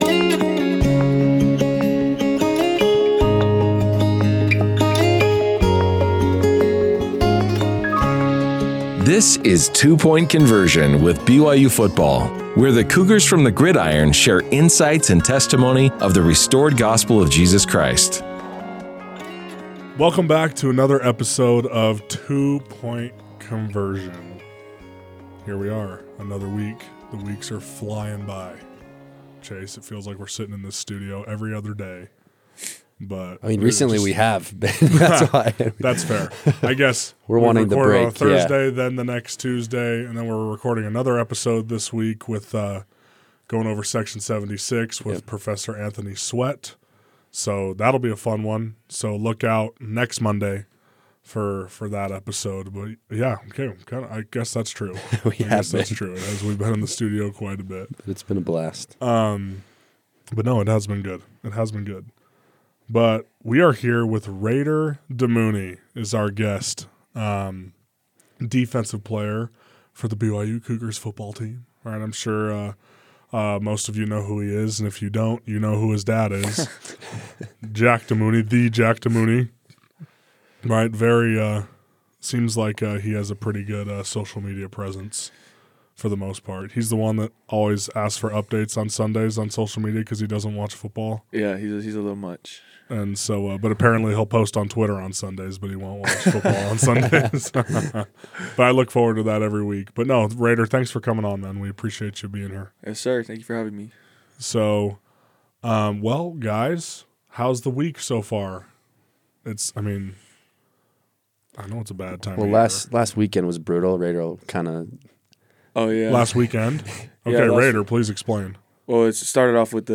for a heartfelt conversation about boldly sharing the gospel of Jesus Christ—even when it's difficult